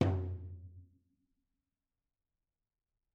TomL_HitS_v3_rr2_Mid.mp3